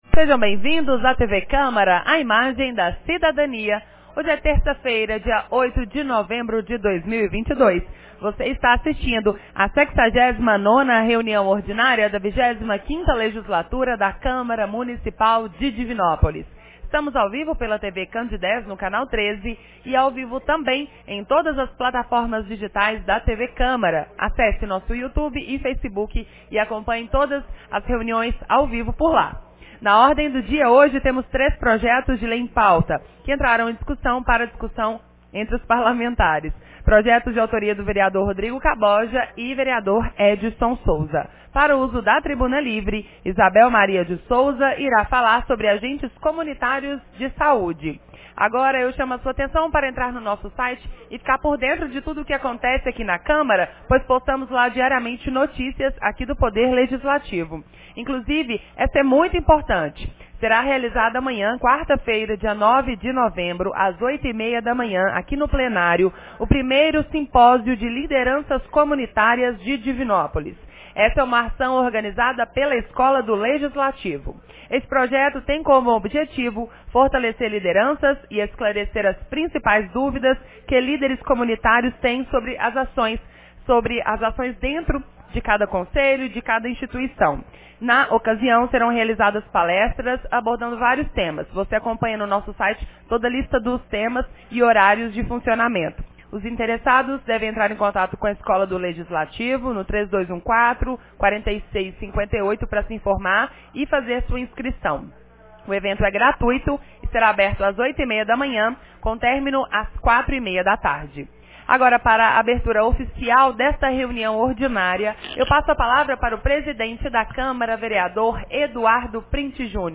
68ª Reunião Ordinária 08 de novembro de 2022